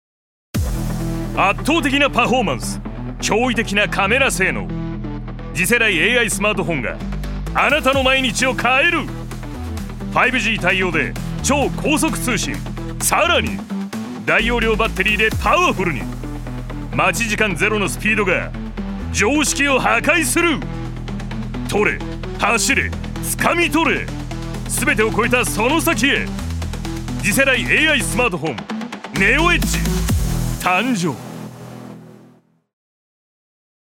所属：男性タレント
ナレーション６